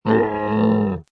Camel 3 Sound Effect Free Download